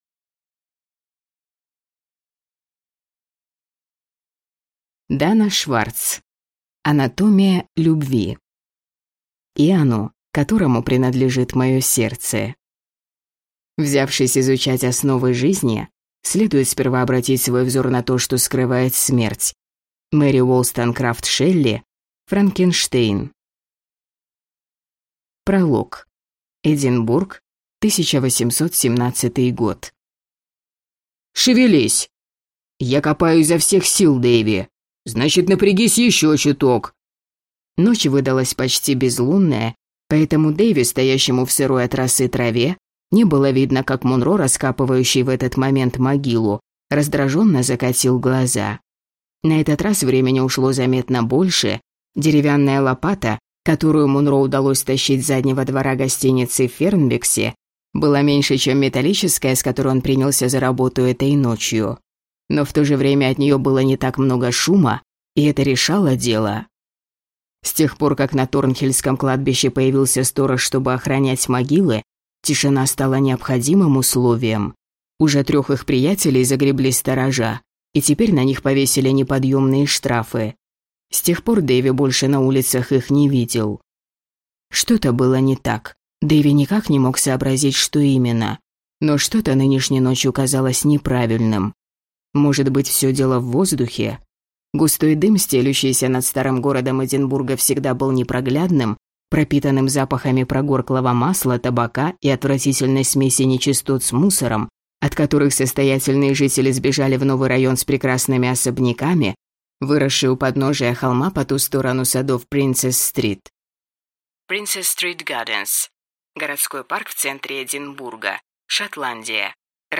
Аудиокнига Анатомия любви | Библиотека аудиокниг